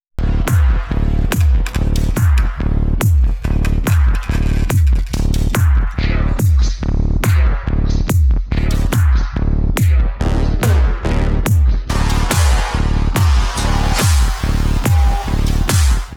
techno-20-el.wav